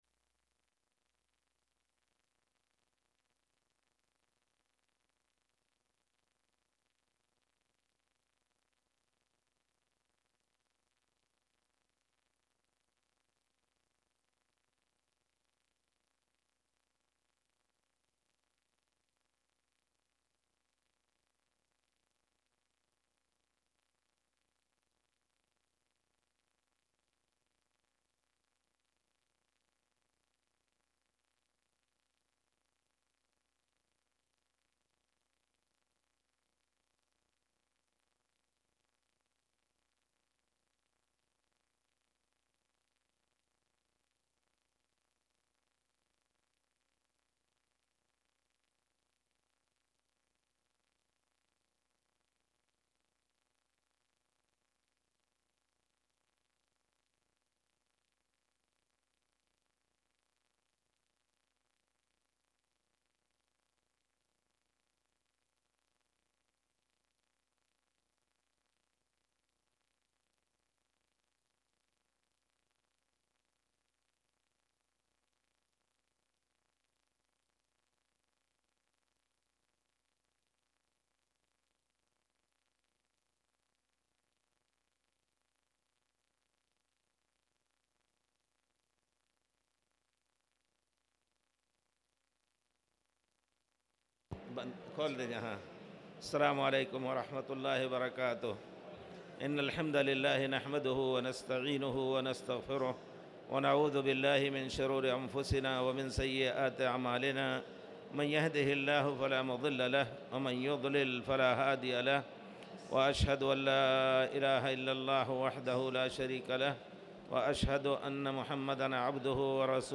تاريخ النشر ١٩ ذو الحجة ١٤٣٨ هـ المكان: المسجد الحرام الشيخ